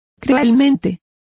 Complete with pronunciation of the translation of cruelly.